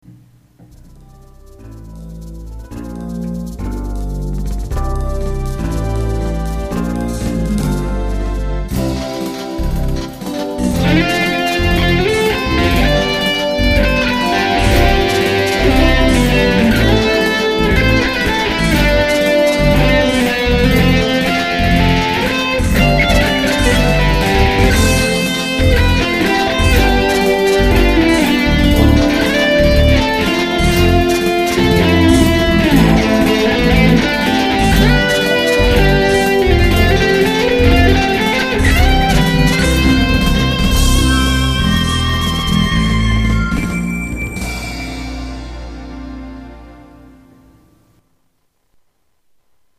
MIDとGuitarの曲